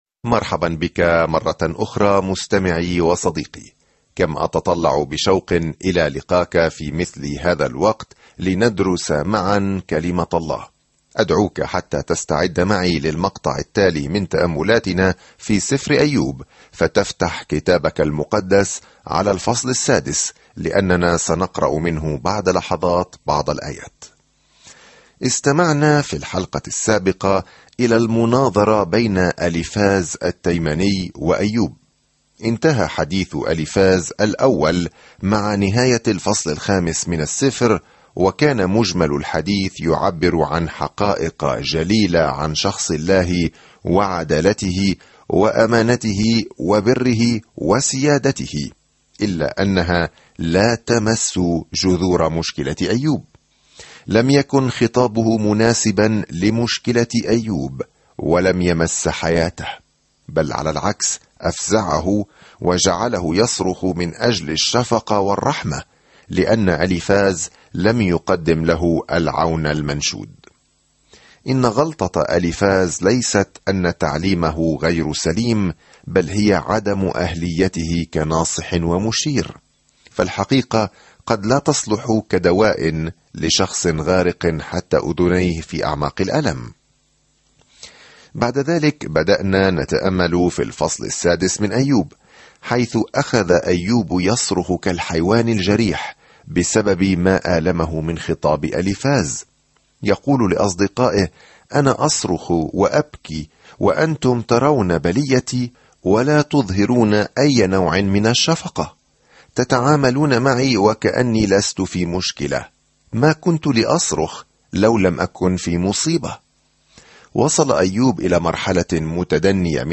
الكلمة أَيُّوبَ 14:6-30 أَيُّوبَ 8 يوم 6 ابدأ هذه الخطة يوم 8 عن هذه الخطة في دراما السماء والأرض هذه، نلتقي بأيوب، الرجل الصالح، الذي سمح الله للشيطان أن يهاجمه؛ كل شخص لديه الكثير من الأسئلة حول سبب حدوث الأشياء السيئة. سافر يوميًا عبر أيوب وأنت تستمع إلى الدراسة الصوتية وتقرأ آيات مختارة من كلمة الله.